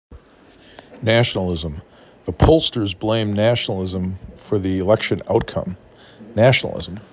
n a · S ə · n ə · l i · z ə m
4 (5) Syllables: NA-tion-al-ism
Stressed Syllable: 1